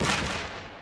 MB Bomb Hit.wav